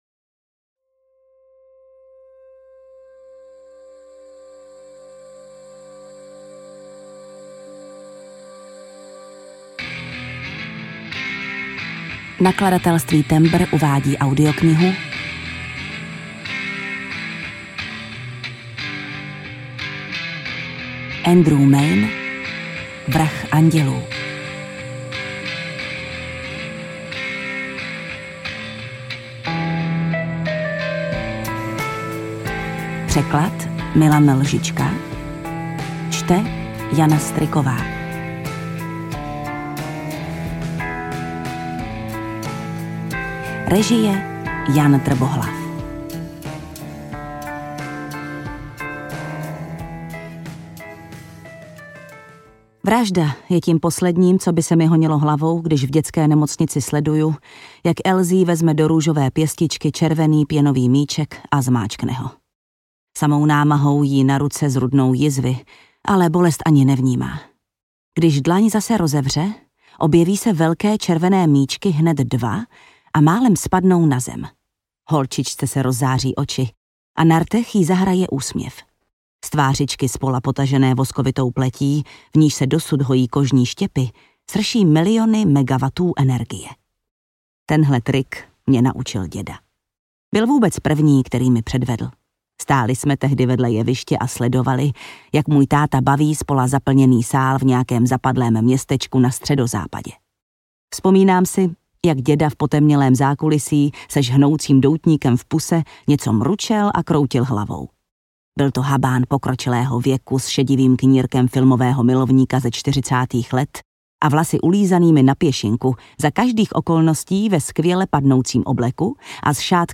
Vrah andělů audiokniha
Ukázka z knihy
• InterpretJana Stryková
vrah-andelu-audiokniha